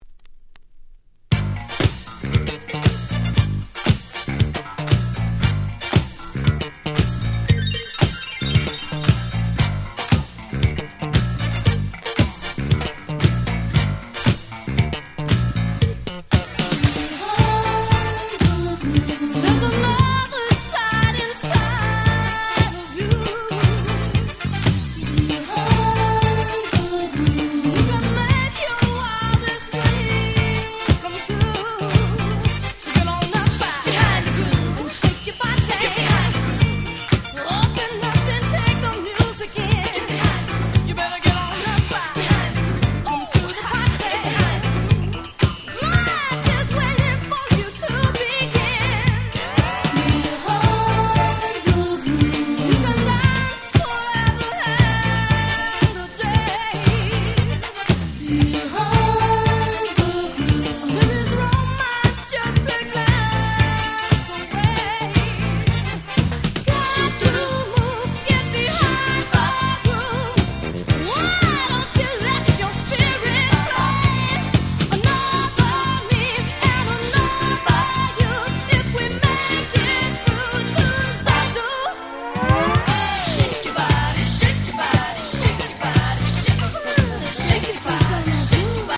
Disco Boogieド定番！！